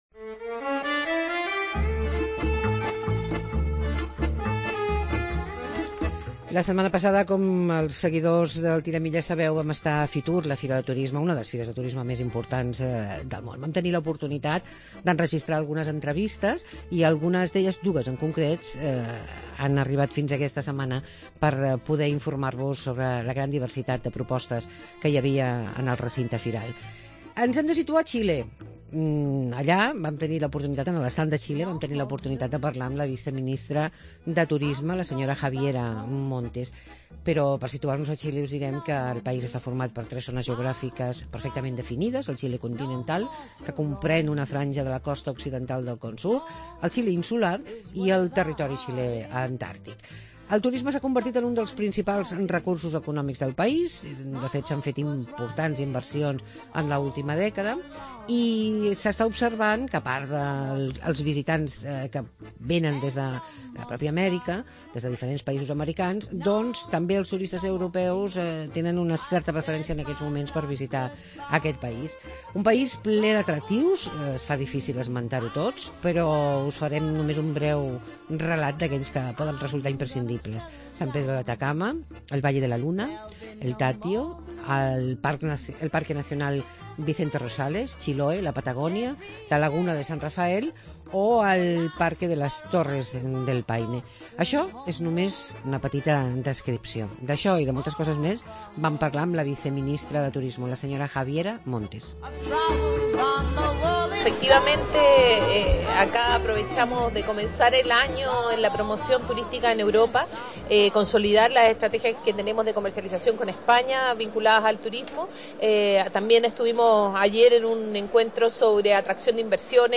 Entrevista a Javiera Montes, viceministra de turismo de Chile